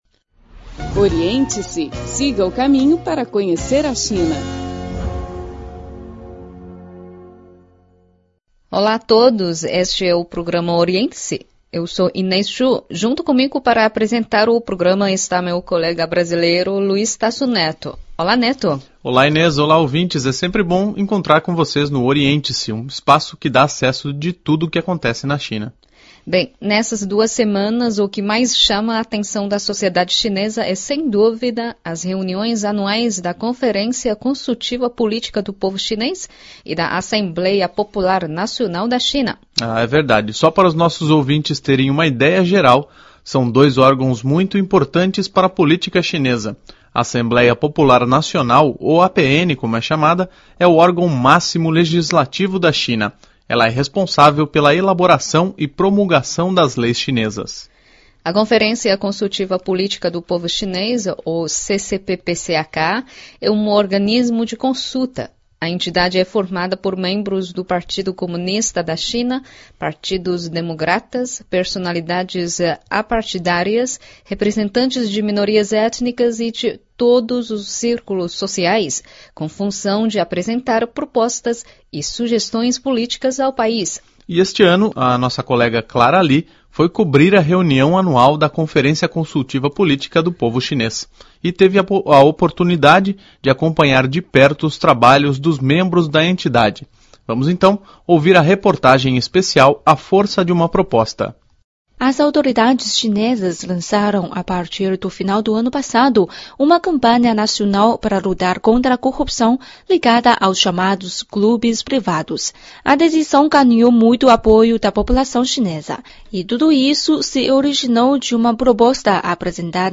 Ouvimos no programa de hoje a reportagem especial A Força de uma Proposta.